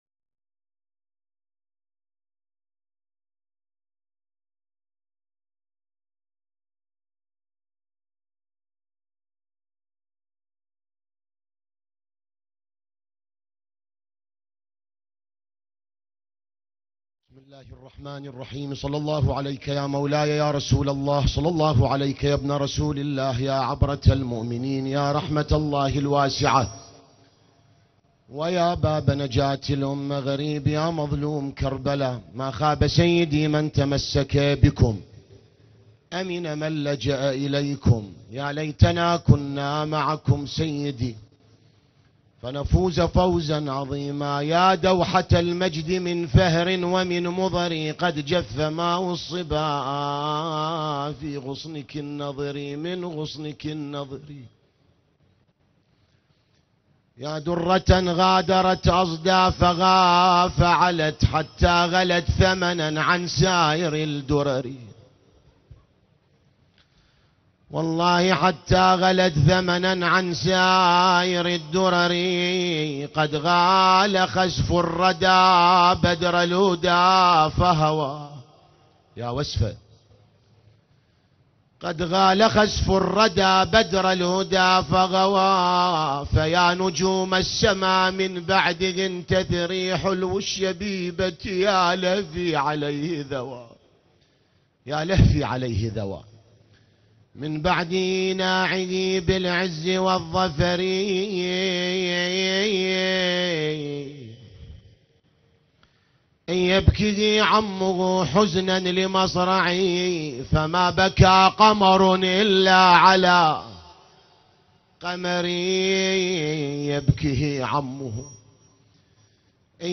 نعي مصيبة القاسم بن الحسن ع النجف الأشرف